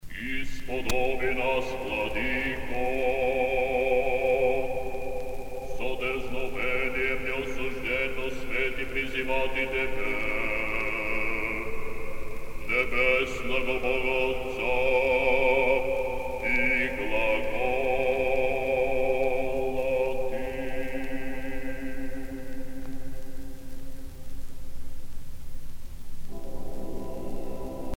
dévotion, religion